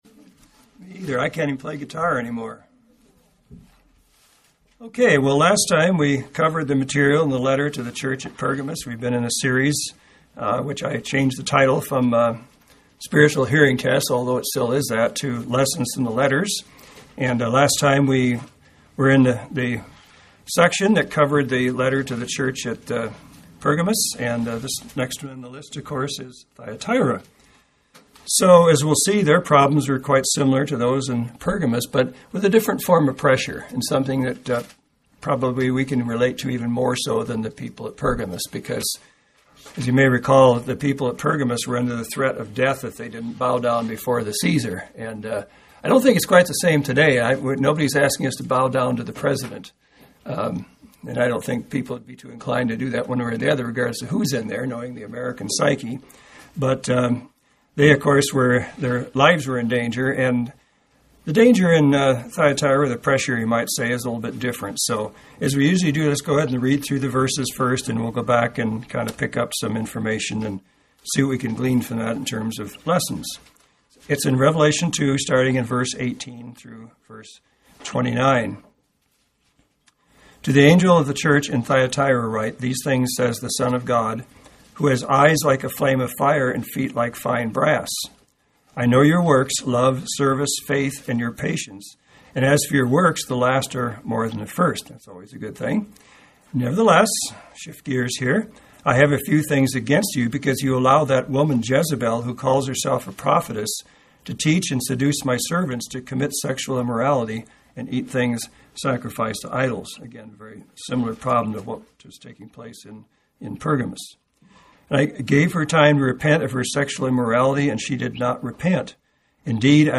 This Bible study series, which began November 6, 2010, analyzes what we can learn from each of these letters.